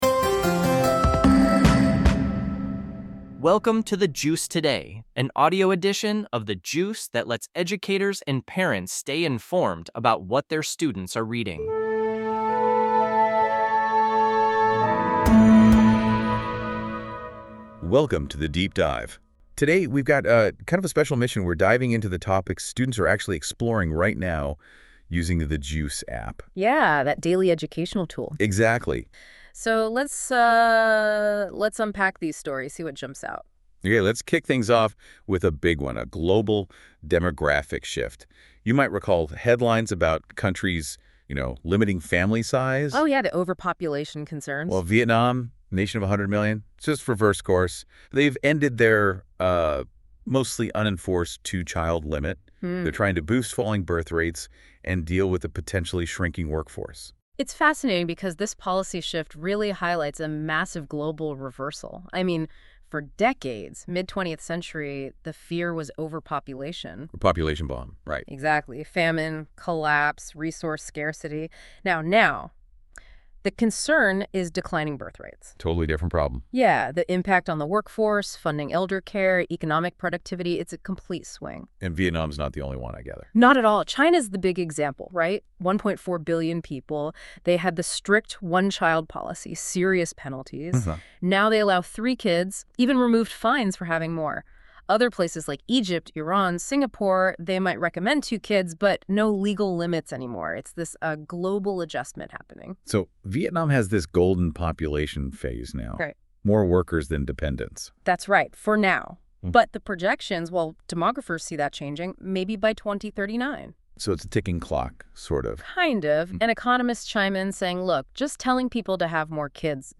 In today's edition: Vietnam Birth Rates US Humidity Spike Farm ICE Raids Homeless Man Fosters Breathing Fingerprints Visit Us Online The Juice Learning (Website) Production Notes This podcast is produced by AI based on the content of a specific episode of The Juice.